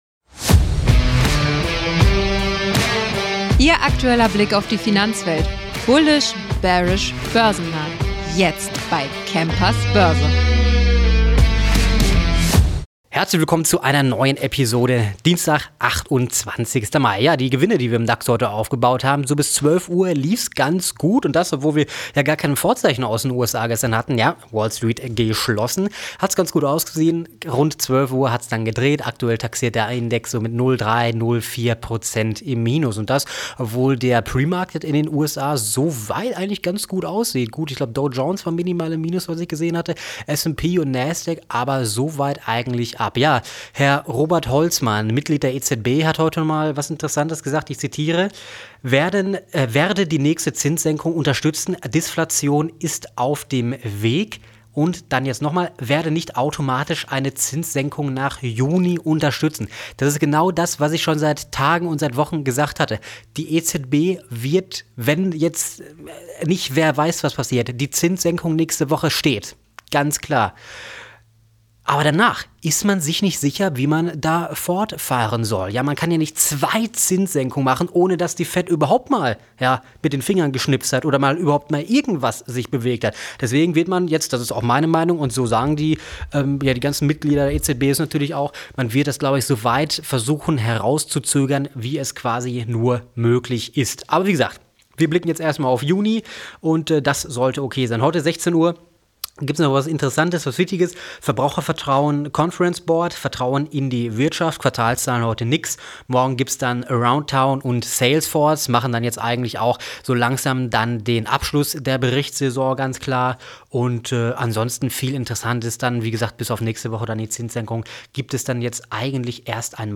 Er verfolgt die heißesten Aktien des Tages, deren Potenzial seine Gäste im Experten-Interview im Anschluss noch einmal genau analysieren.